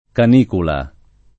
canicula [ kan & kula ] → canicola